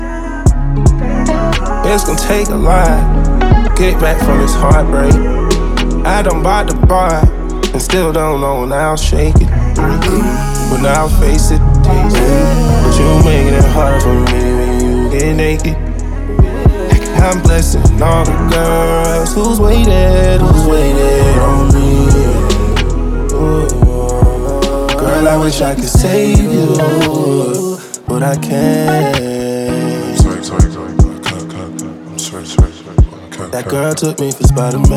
R B Soul